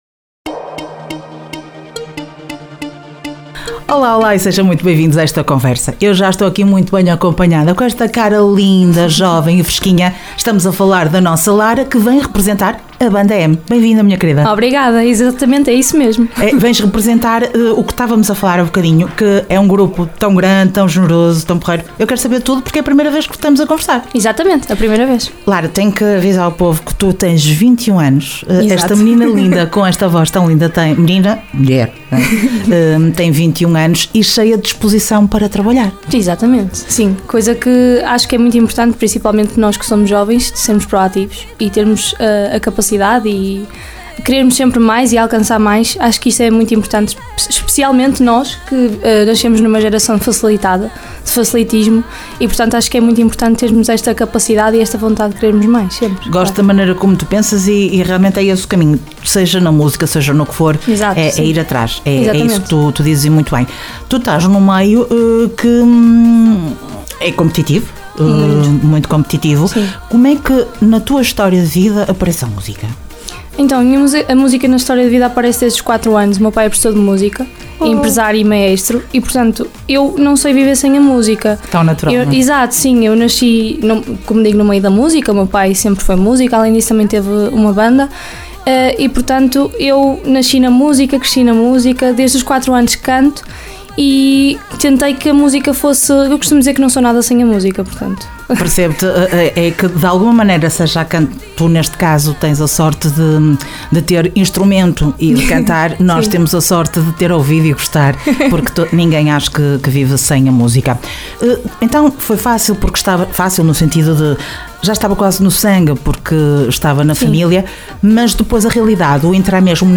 Entrevista Banda M dia 29 de Maio 2025.
ENTREVISTA-BANDA-M.mp3